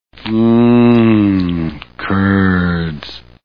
The Simpsons [Celebrities] Cartoon TV Show Sound Bites